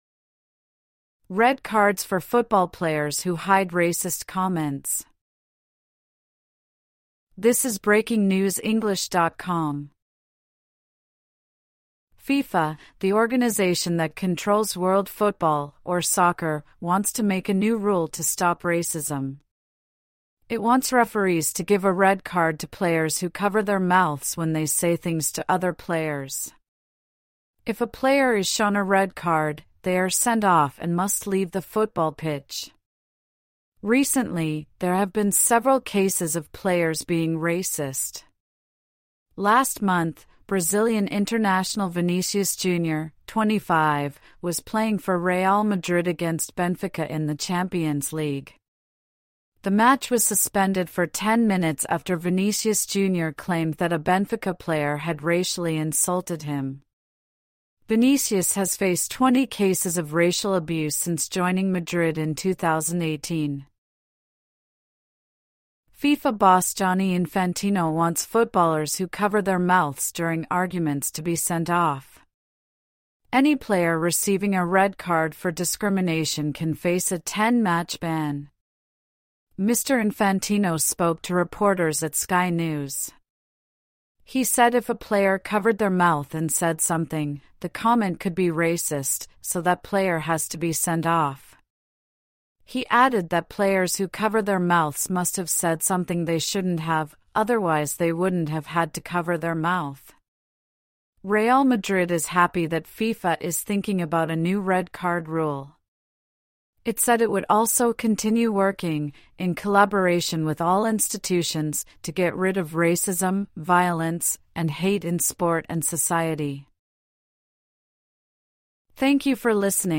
AUDIO(Normal)